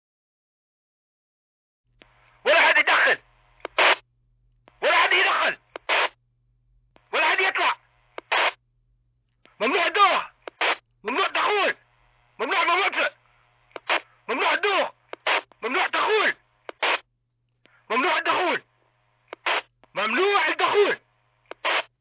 Арабская речь в переговорах солдат по рации